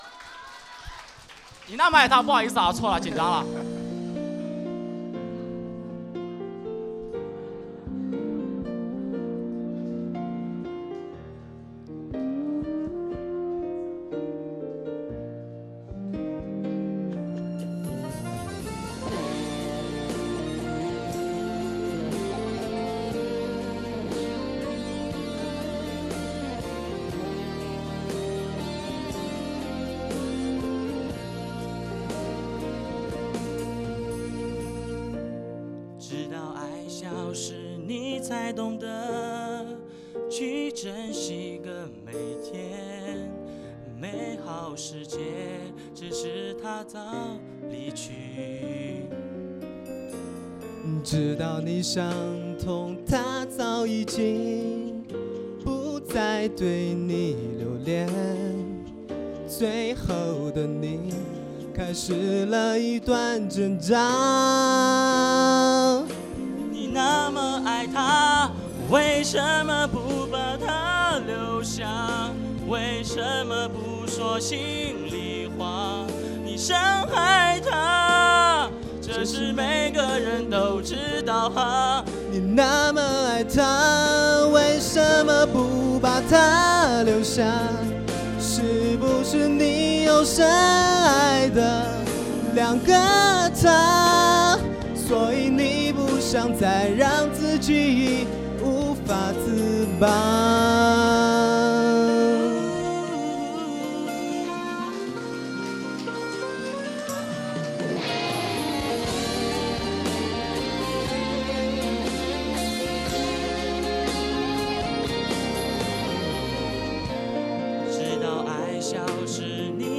La finale du 1er concours de chansons chinoises
le lundi 9 mai à 18h30 à l'Espace Culture de l'Université Lille 1